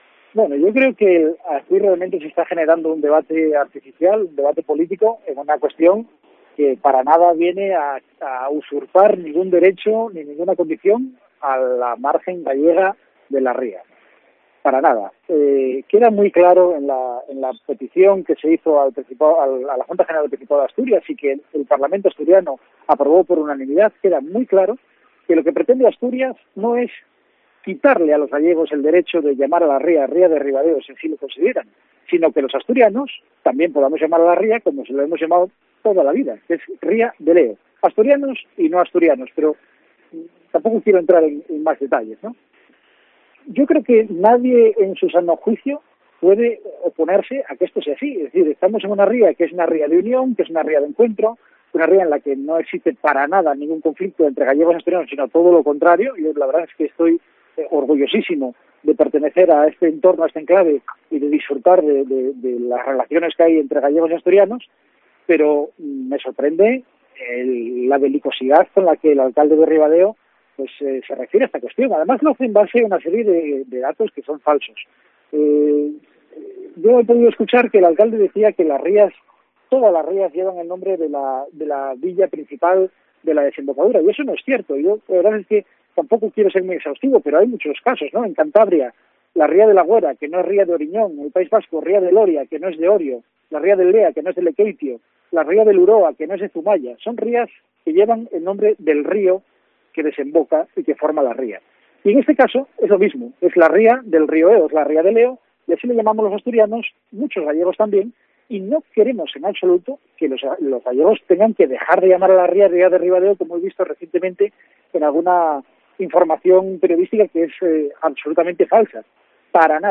Declaraciones de Álvaro Queipo, diputado del PP por el Occidente Astur